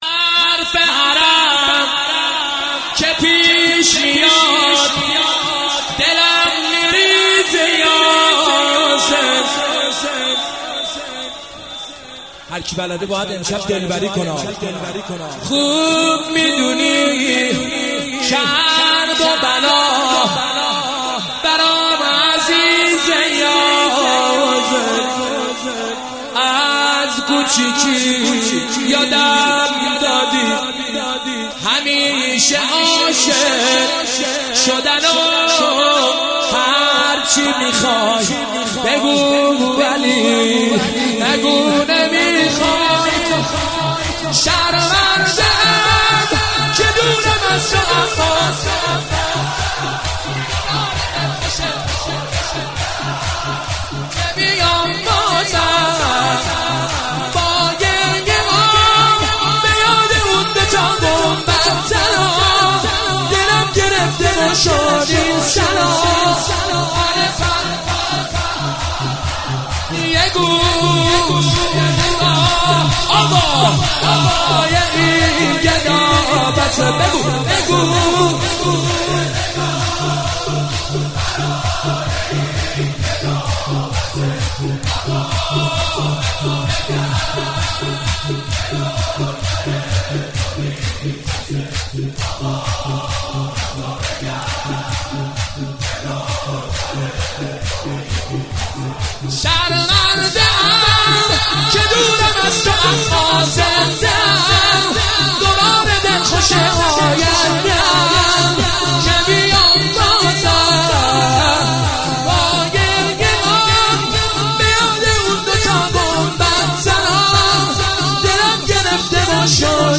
(شور جدید)